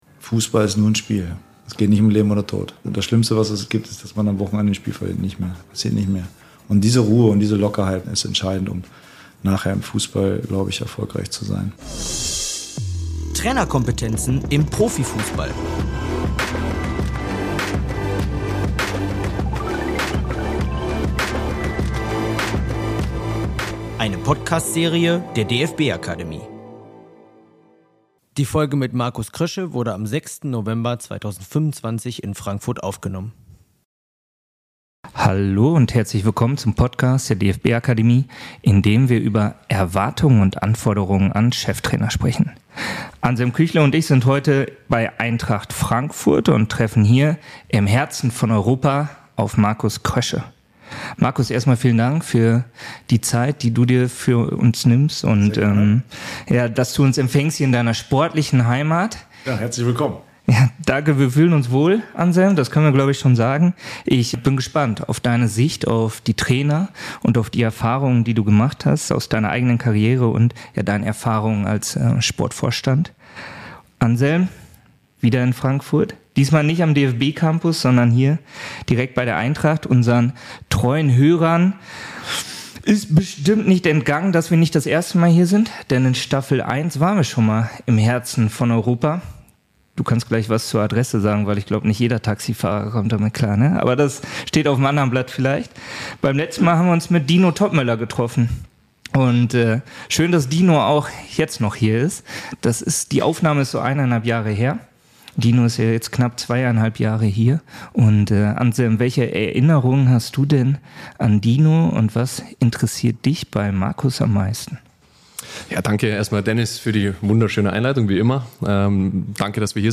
Die Podcast-Folge wurde am 10. November 2025 in Schwetzingen aufgenommen.